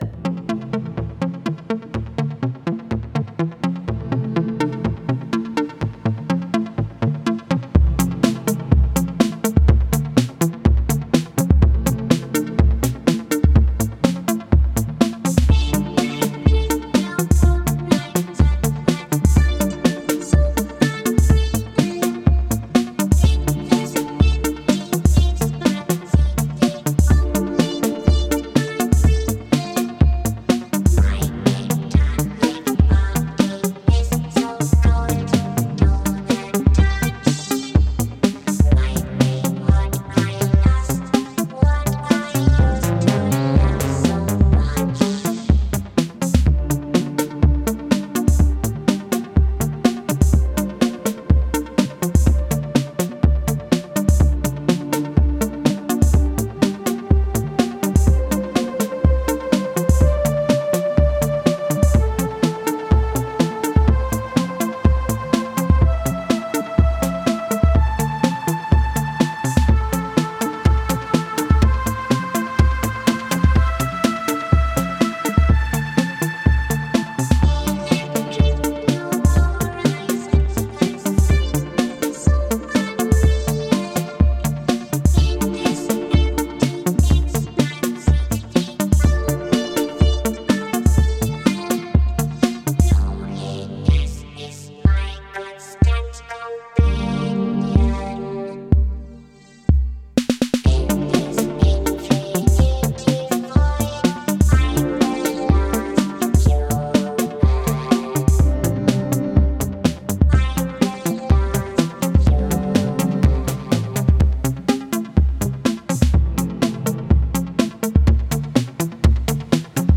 a heavy dose of melodic, vocoded vocals
Dreamy, lush, and full of character.